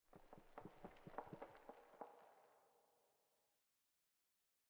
pale_hanging_moss11.ogg